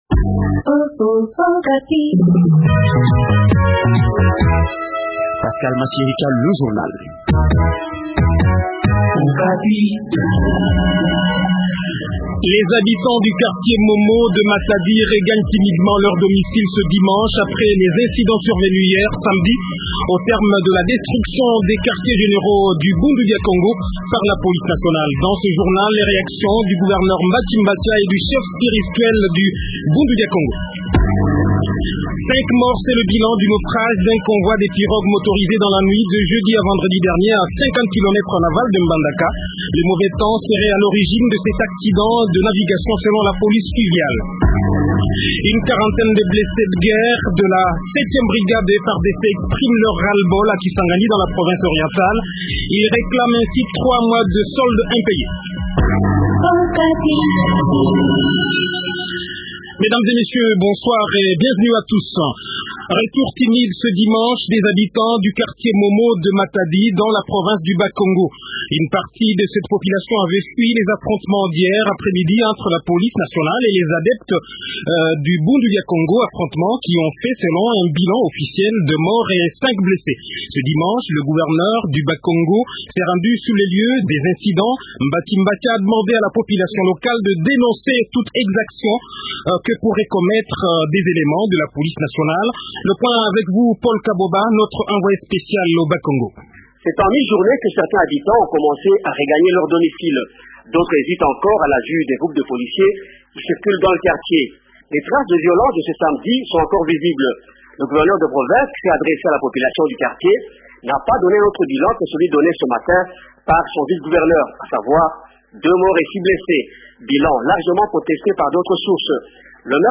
1. Matadi : les habitants du quartier momo de matadi regagnent timidement leurs domicile ce dimanche après les incidents survenus hier, au terme de la destruction des quartiers generaux du bdk par la police. Dans ce journal les reactions du gouverneur Mbatshi et du chef spirituel de bundu dia kongo rn rn2.